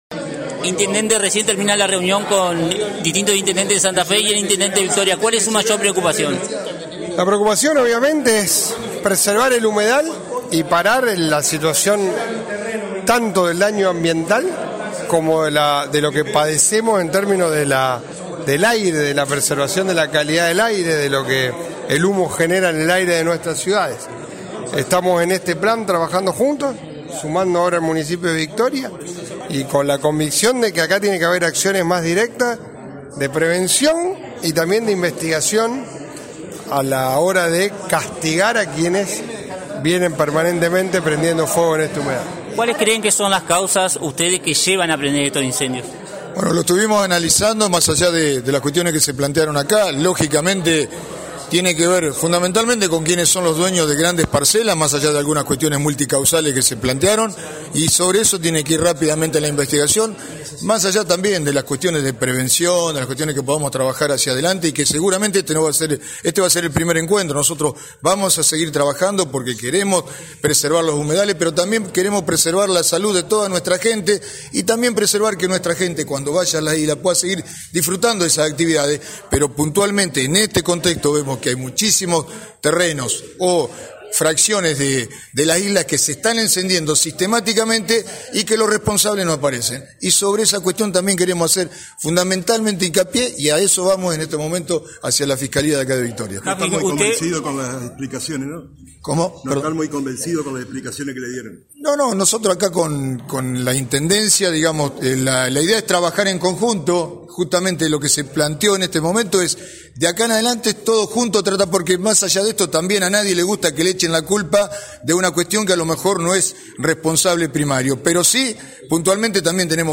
En diálogo con nuestros periodistas, los intendentes de rosario y Victoria coincidieron en la necesidad de un trabajo en conjunto, y en profundizar la investigación en determinados sectores donde los incendios se reiteran en forma inusual.
Intendente de Rosario Pablo Javkin